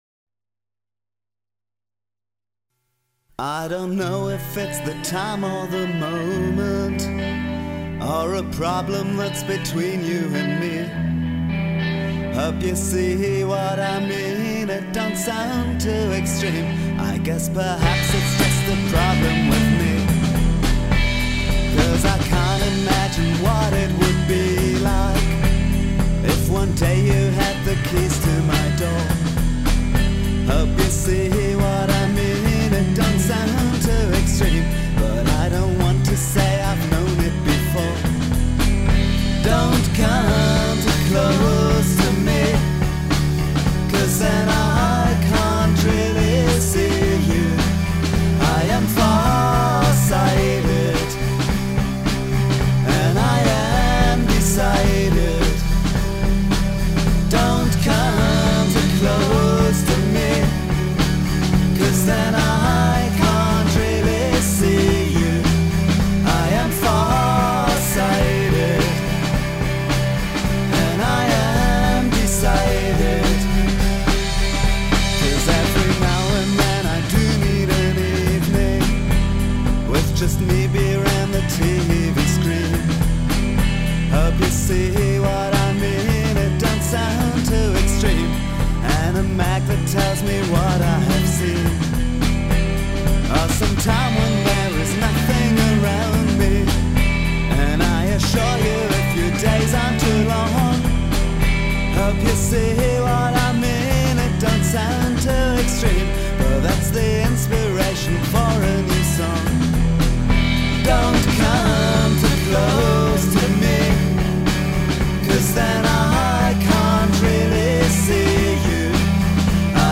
The first album from homerecording sessions 2005/06